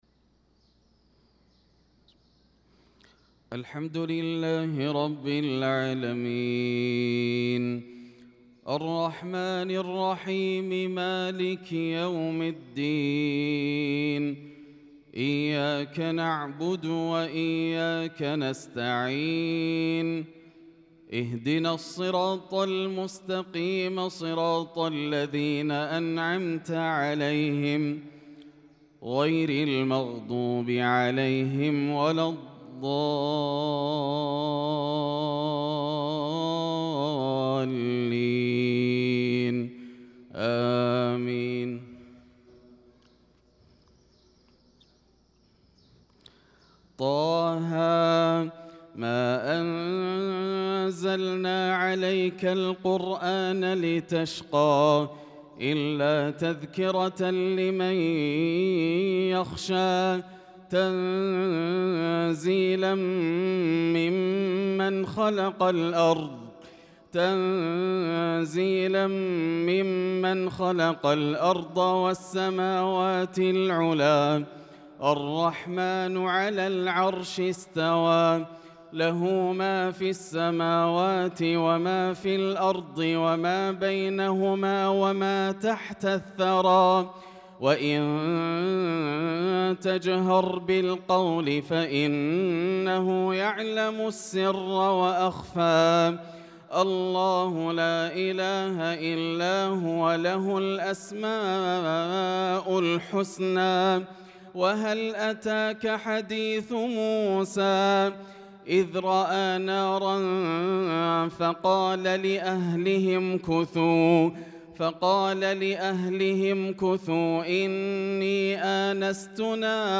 صلاة الفجر للقارئ ياسر الدوسري 25 شوال 1445 هـ